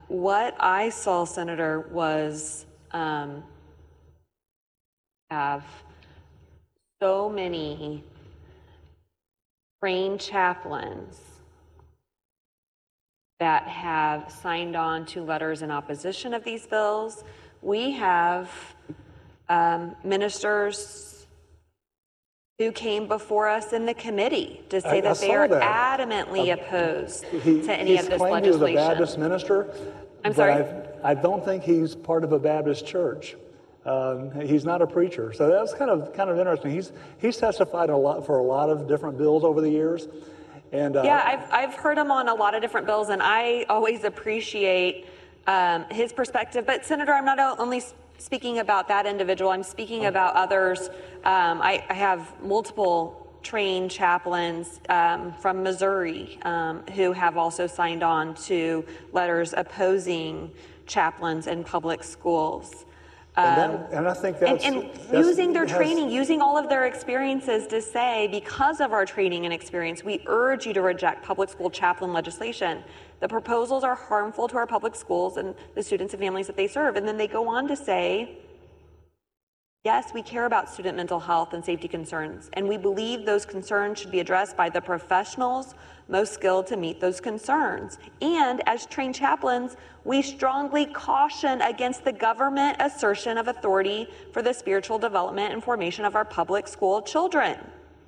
Democratic Sen. Maggie Nurrenbern, who is Catholic, expressed concerns about the bill during the Senate debate.
As they talked, Nurrenbern noted the religious opposition to the bill, leading to an exchange where they cut in a bit on each other: